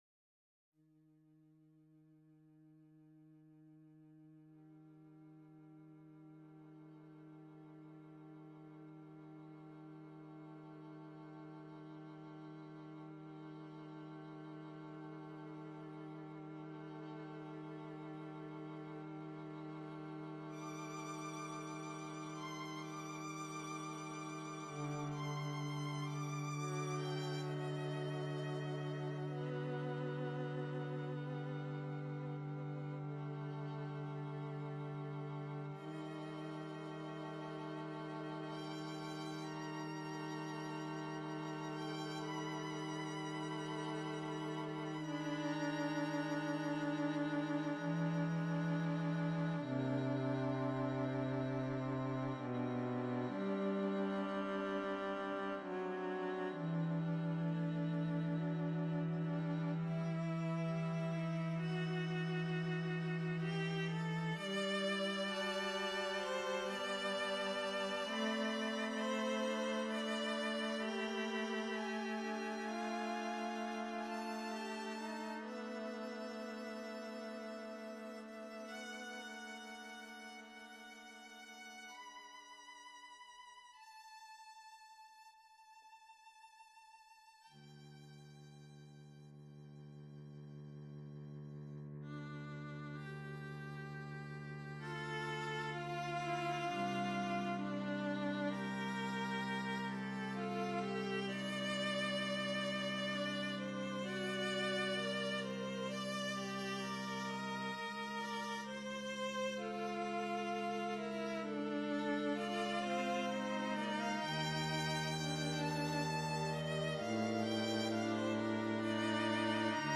String Quartet No. 2 – A Rural Substitute (2 violins, viola, and cello) – 2009 | Roman Designs
String Quartet No. 2 – A Rural Substitute (2 violins, viola, and cello) – 2009